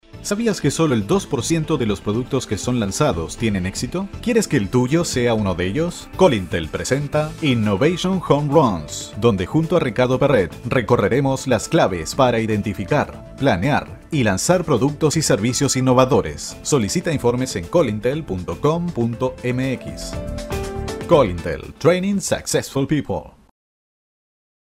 Voz joven, agil y dinamica para potenciar su marca o proyecto !!
Sprechprobe: Industrie (Muttersprache):
Voice over spanish